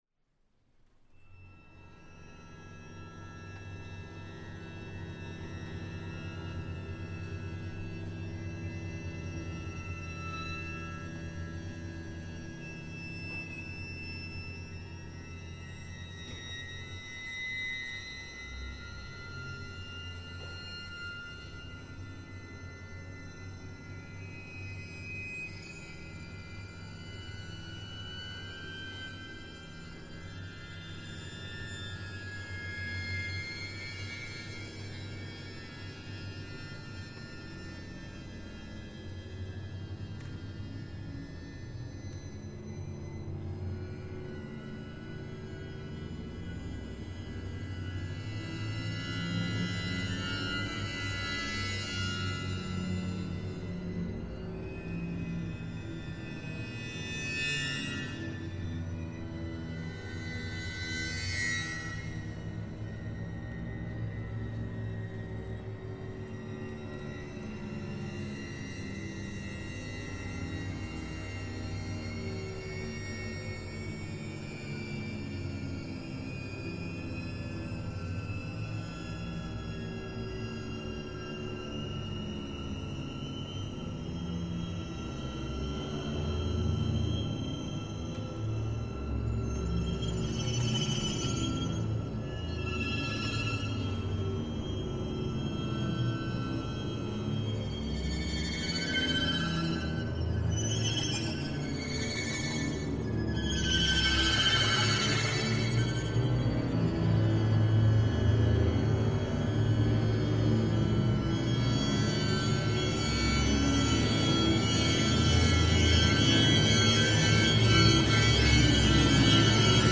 Streicherbesetzung 6/5/5/5/4
Premiere am 13.09.23 im hr-Sendersaal